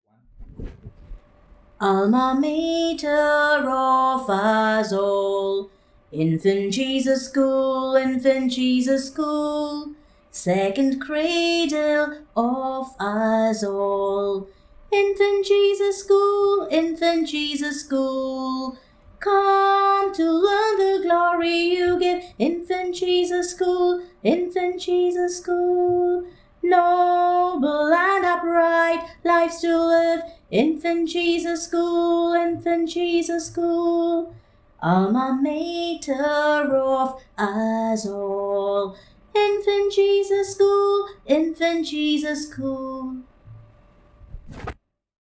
School Anthem